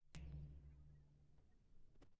Metal_72.wav